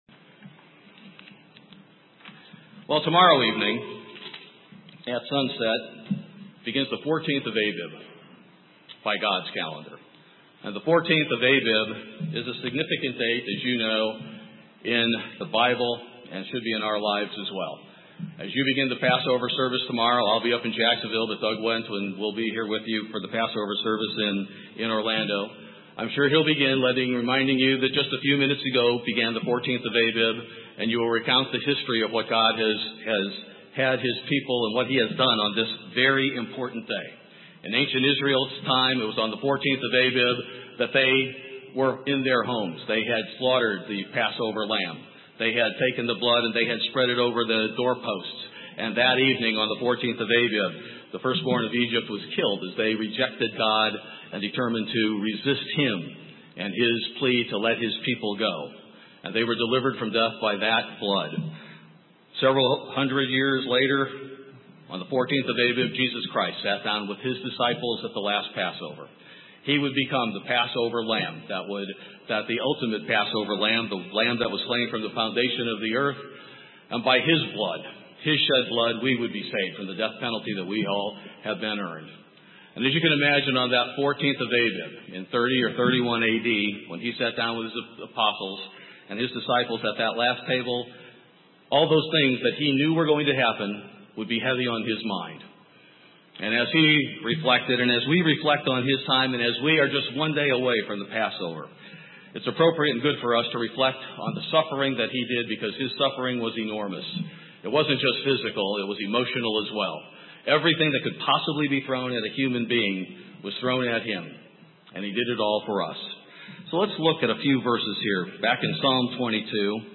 In this sermon, we will look at three attitudes that Jesus Christ faced on the last day of His life that led to His killing.